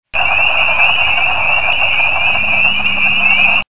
Wood Frog Rana sylvatica HEAR THIS FROG'S CALL
woodcall.mp3